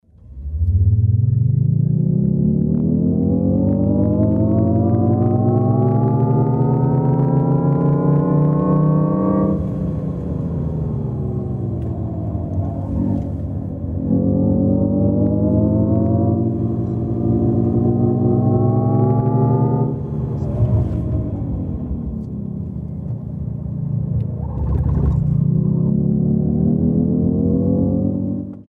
Звуки электромобиля
На этой странице собраны звуки электромобилей — от мягкого гула двигателя до предупреждающих сигналов.